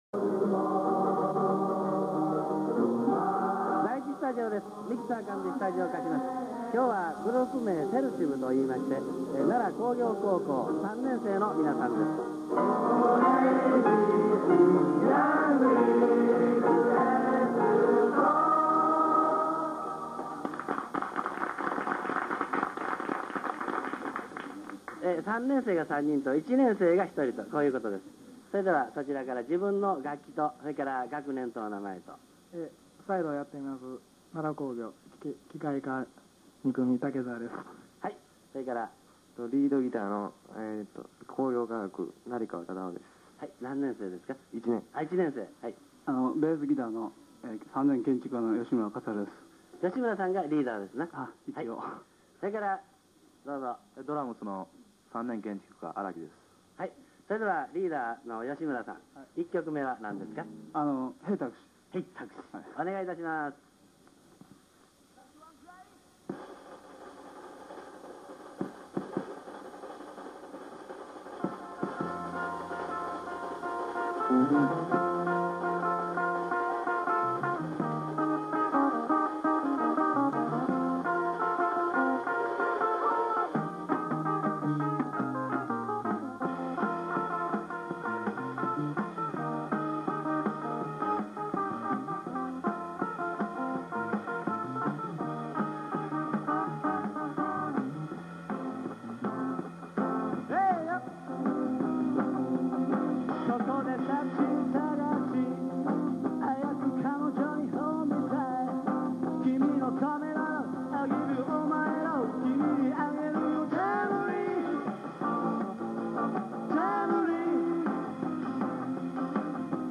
だからアンプから流れる爆音の中で自分の声を頼りに唄わないといけないという辛いものだったが、元々ＰＡなどというちゃんとした環境で演奏出来ないことには慣れていた僕達だったから何とか対処出来た。それ以上に残念だったのが貧弱なドラムセットで、スネアはスナッピーがなく、ポコポコと鳴るまるで飴屋の太鼓のようで、こんなことなら自前のスネアを持って来るんだったと悔やんだが、今更どうにもならない事と諦めた。
局からはヤンリクノートだのステッカーだのと色々もらい、交通費とモノラルではあるがオープンリールの収録テープを貰って帰った。
最初のテーマソングから入っている。
ミキサーで音を絞り過ぎてギターなどは本当にアンプを通しているのかと疑いたくなるくらいのペンペンした音、ヴォーカルも最初はノン・リバーブで、どういうワケか途中からリバーブが立ち上がるといった不自然なもので、スタジオで演奏した迫力ある音源にはほど遠いものになってしまっていた。
音質が悪いというよりカッコ悪いんですが（笑）